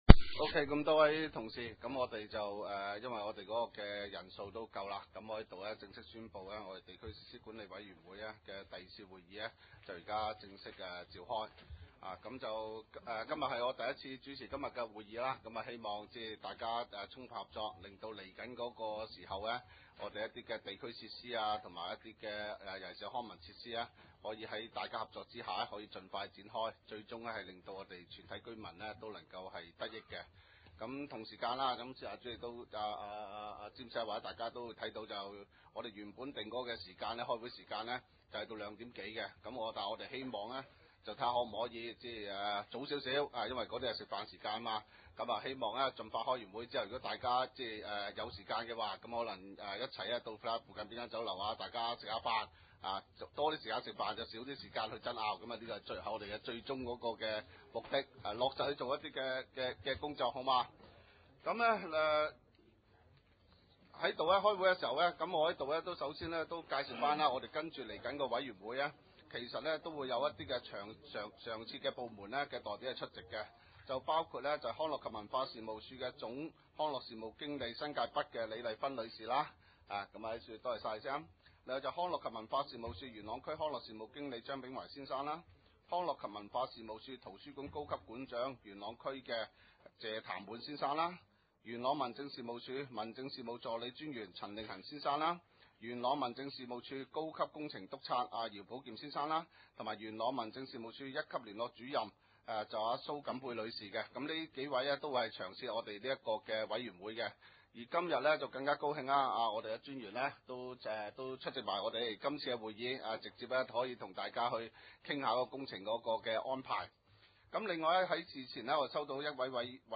點： 元朗區議會會議廳